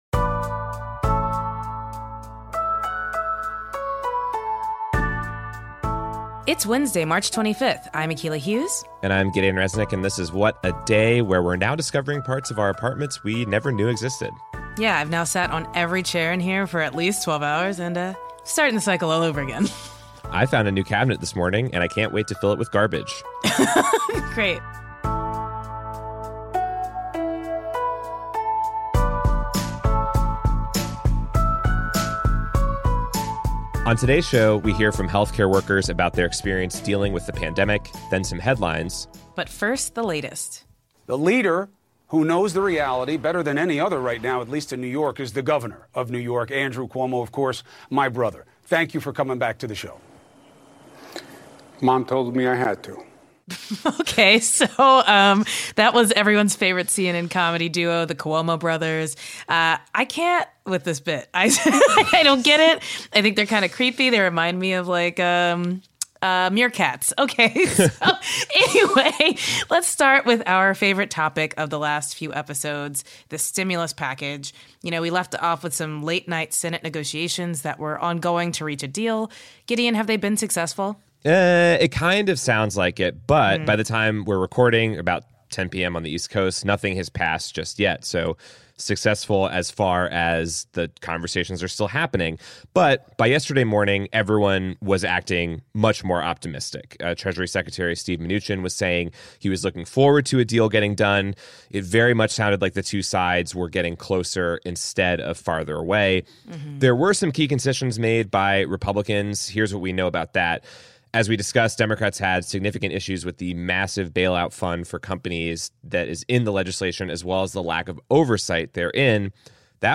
Equipment shortages, exposure, and empathy: we hear from healthcare workers who are fighting Covid-19 on the front lines.